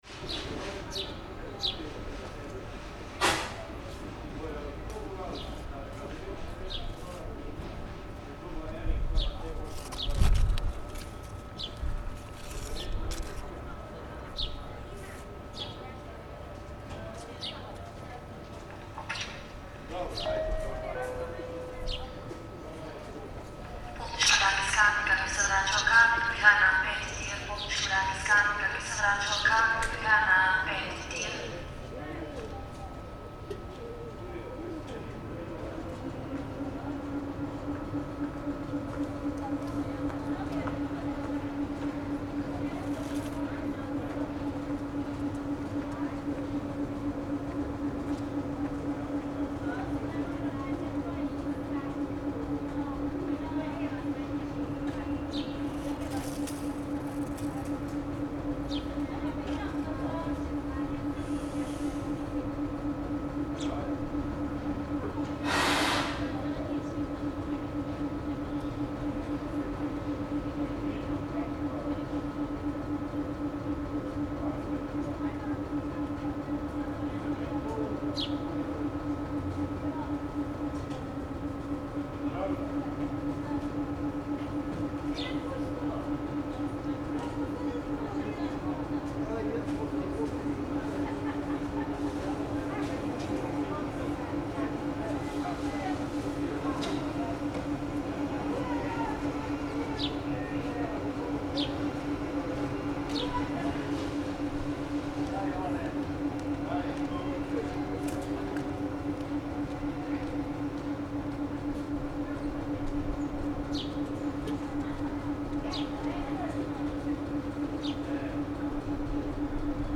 Ljubljana Railway Station
Somehow everything seemed to fit together on this summer day: the late afternoon sun, the song of the swallows, the melodic train announcements creating harmonies with the sound of the train engine, people’s voices drifting past like the leaves being blown around by the wind.
Ljubljana-Railway-Station.mp3